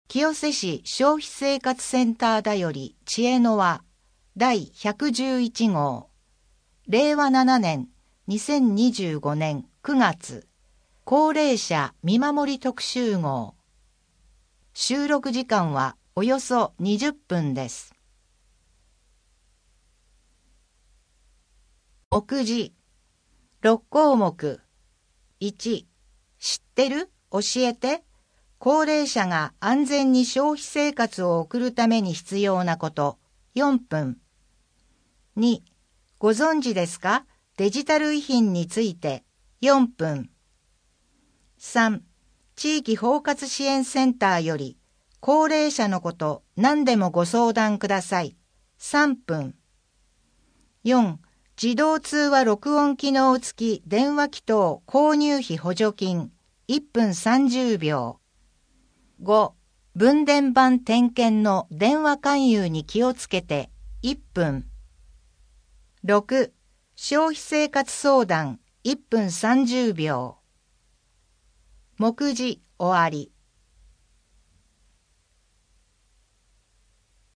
消費生活相談 音声データ 声の広報は清瀬市公共刊行物音訳機関が制作しています。